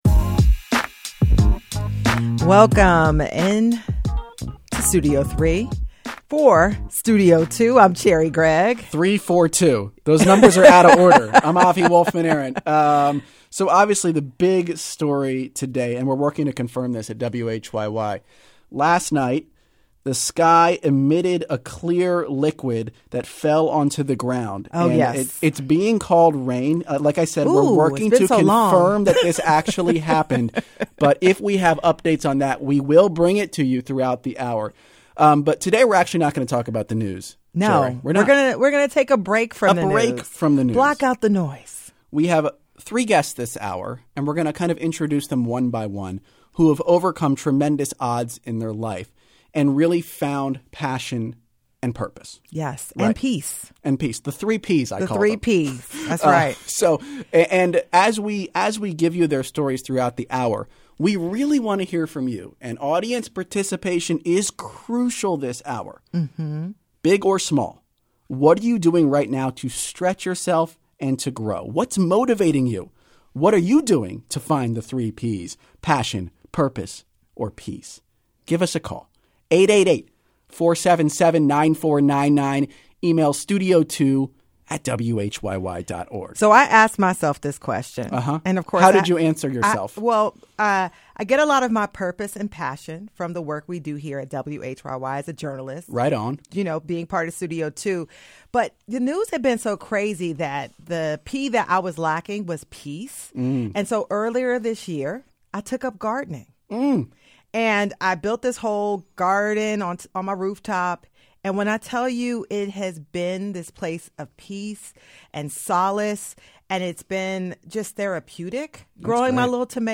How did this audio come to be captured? Dignitaries and politicians gathered on Independence Mall Monday to honor the late Nelson Mandela in the annual Martin Luther King Day ringing of the Liberty Bell.